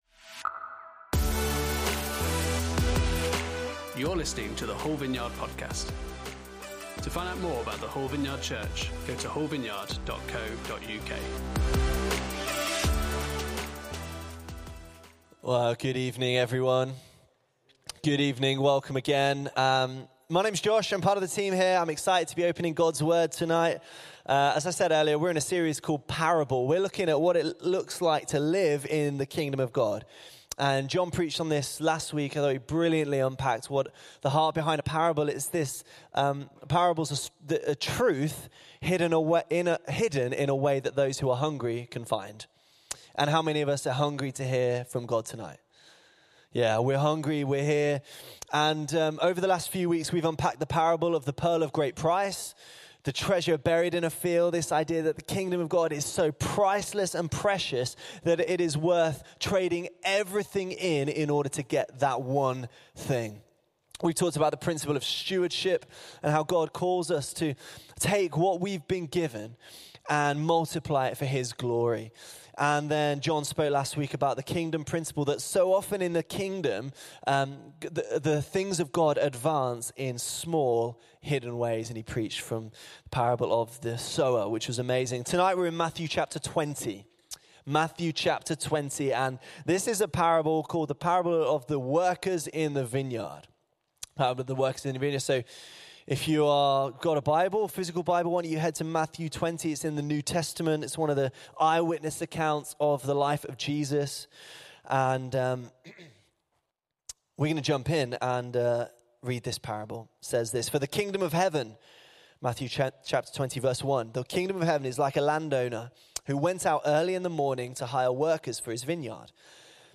Parable Service Type: Sunday Service On Sunday evening